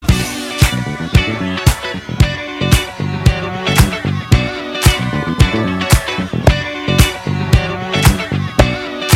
old-school-sound_23068.mp3